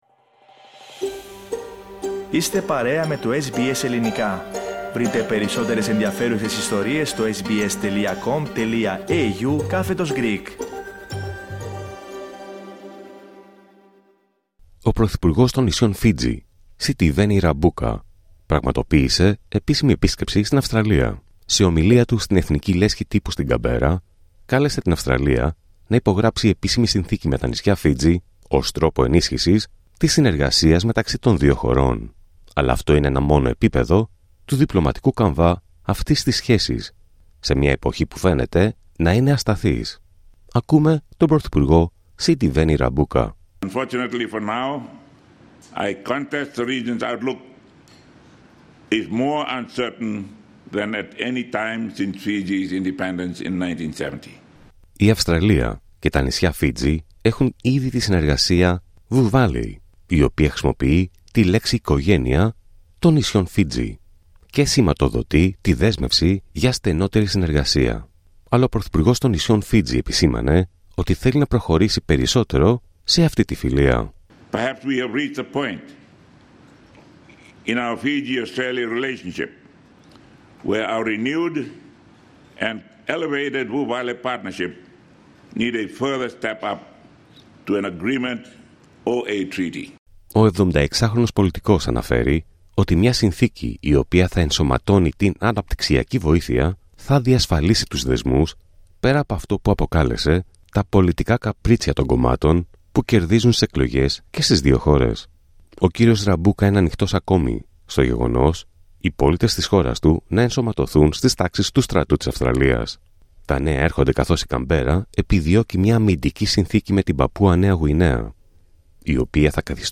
Prime Minister of Fiji Sitiveni Rabuka addresses the National Press Club in Canberra, Wednesday, July 2, 2025.